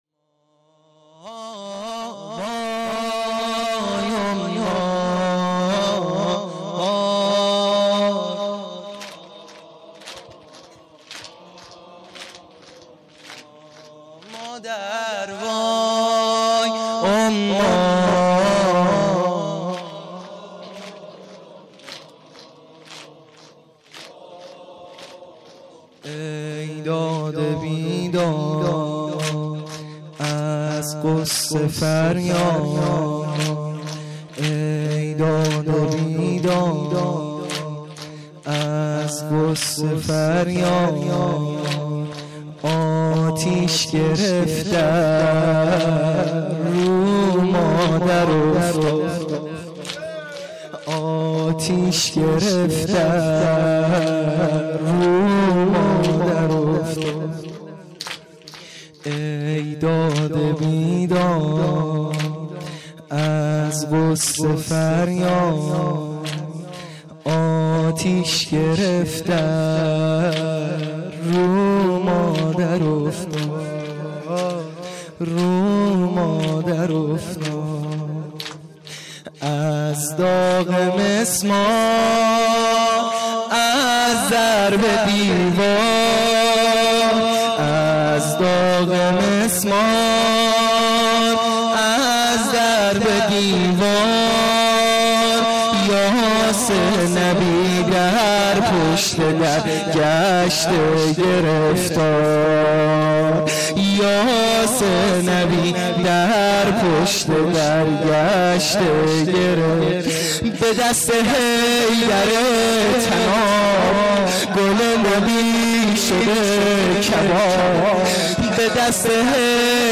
زمینه | ای داد و بیداد از غصه فریاد